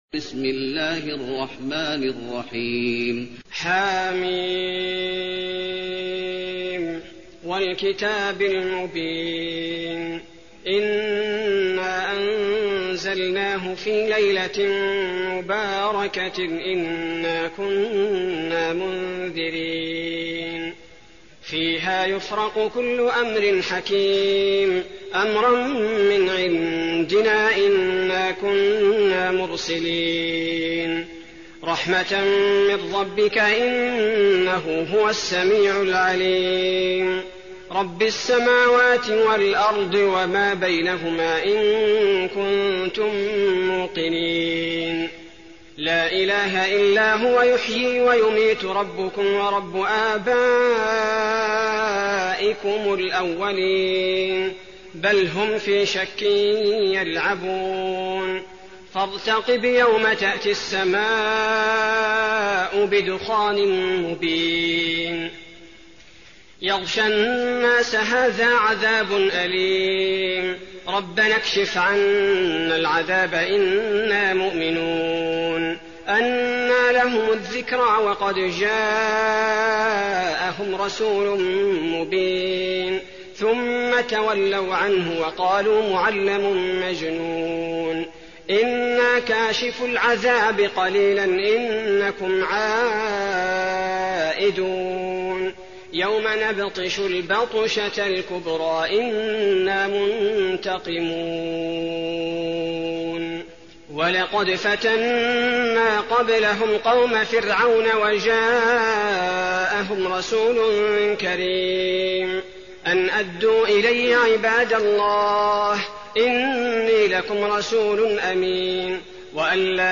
المكان: المسجد النبوي الدخان